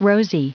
Prononciation du mot rosy en anglais (fichier audio)
Prononciation du mot : rosy